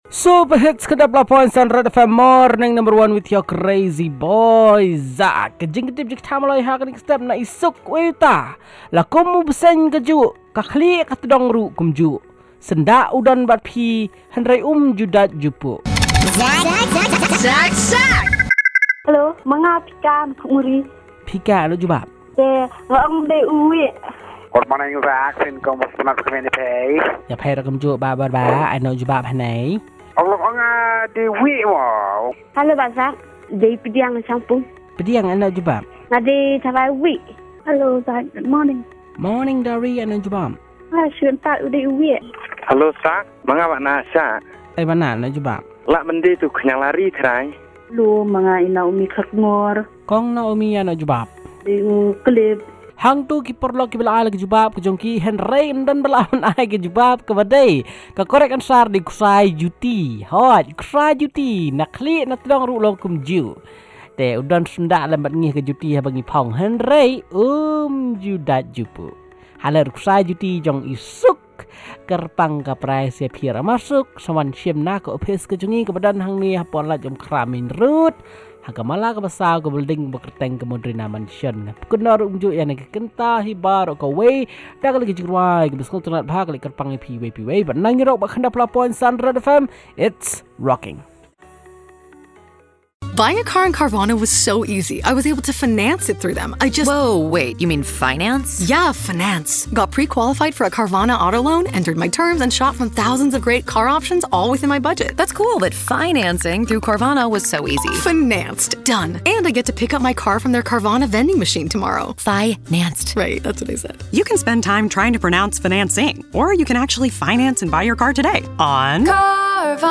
calls and results